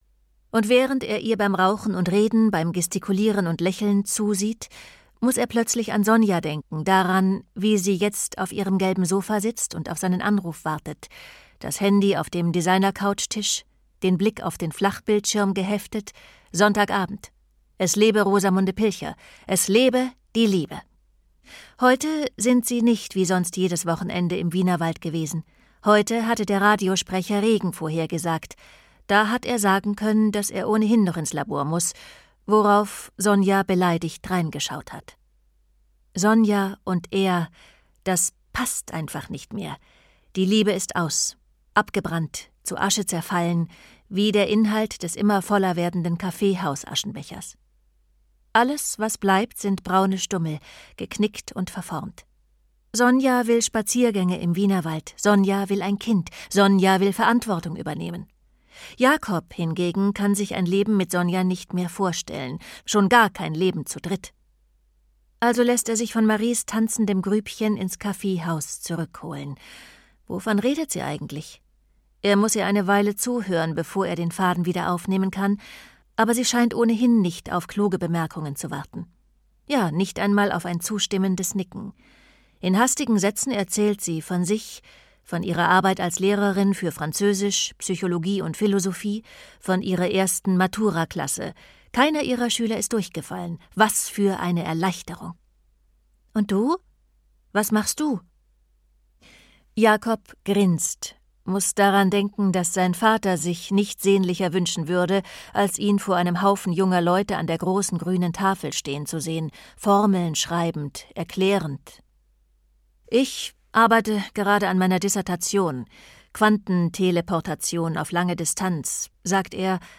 Mittelstadtrauschen - Margarita Kinstner - Hörbuch